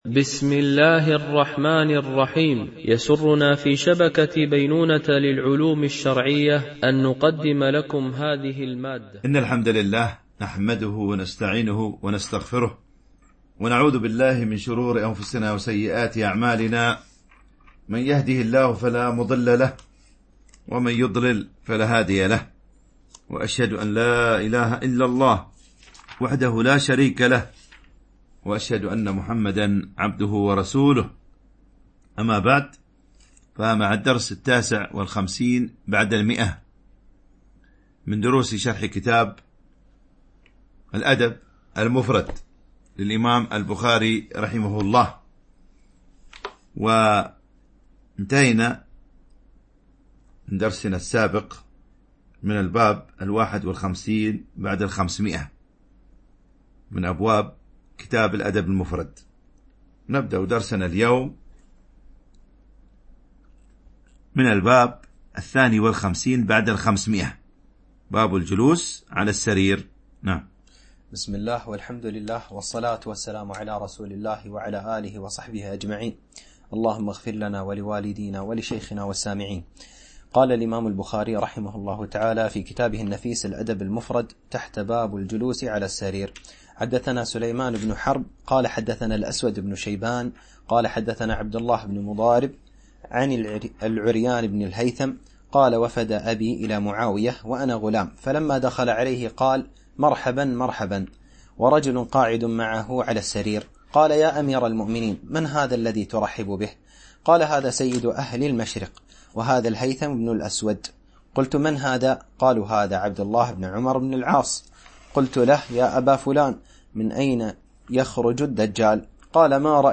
شرح الأدب المفرد للبخاري ـ الدرس 159 ( الحديث 1160 - 1167 )